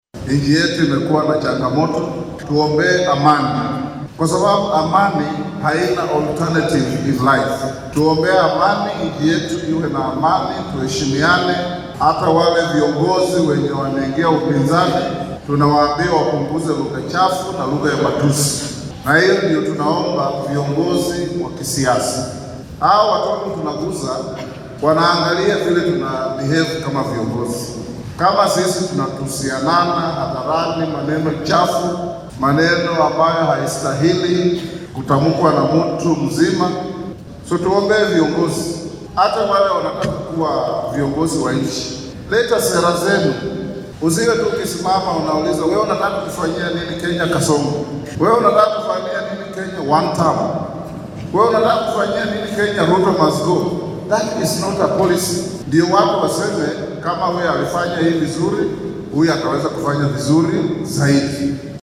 Xilli uu maanta munaasabad kaniiseed uga qayb galay deegaanka Kanduyi ee ismaamulka Bungoma ayuu codsaday in madaxda loo duceeyo.